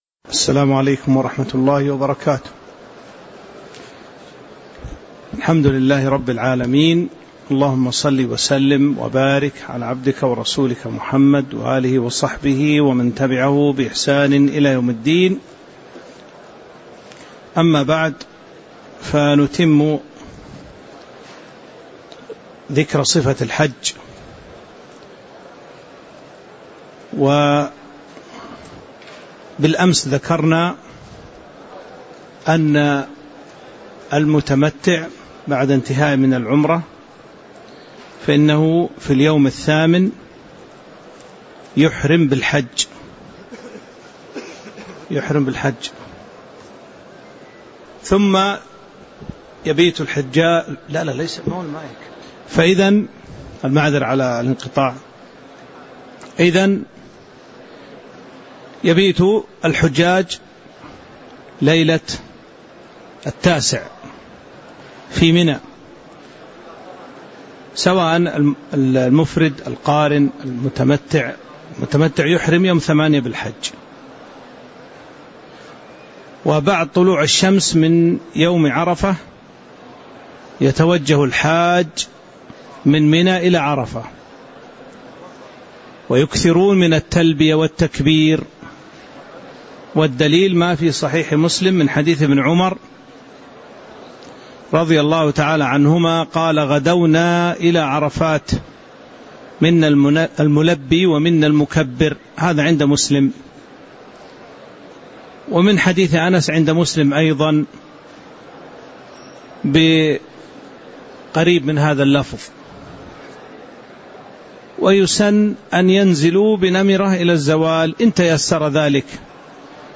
تاريخ النشر ٦ ذو الحجة ١٤٤٦ هـ المكان: المسجد النبوي الشيخ